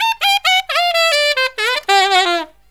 63SAXMD 08-R.wav